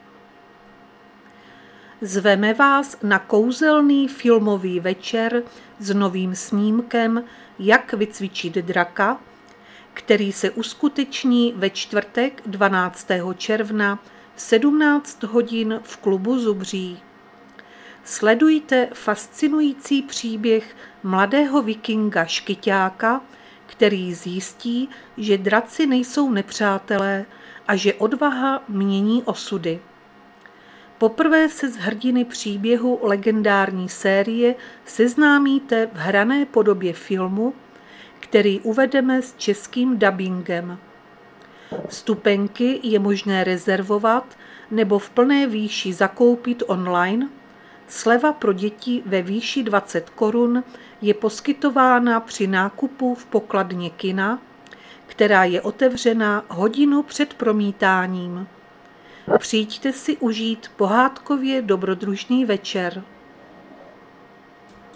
Záznam hlášení místního rozhlasu 12.6.2025
Zařazení: Rozhlas